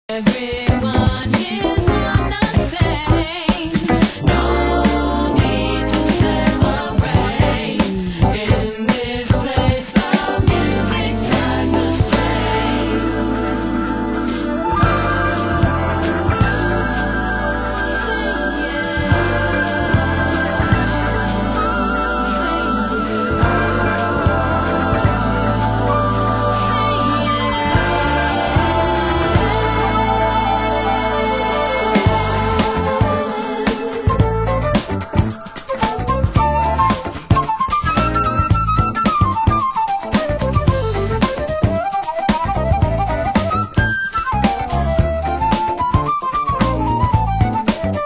バンド形態のジャジー・オーガニックソウル！